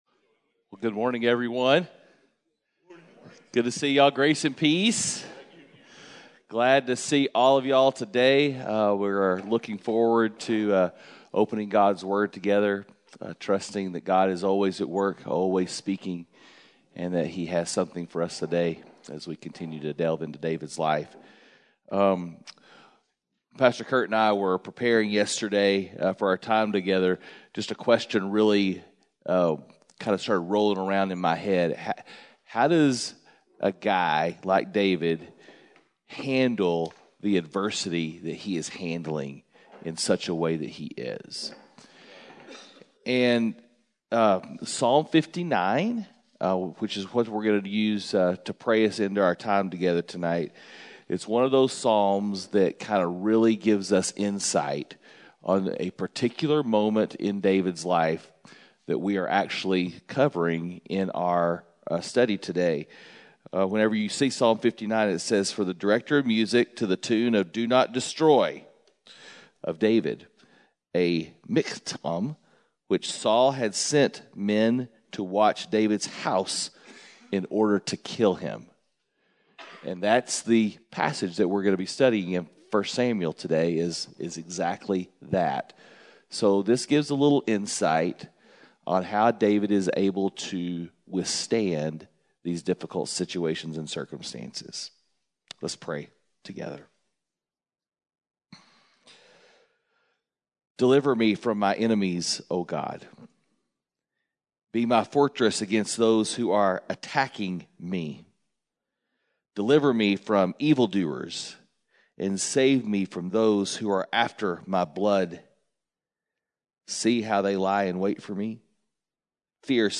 Men’s Breakfast Bible Study 3/30/21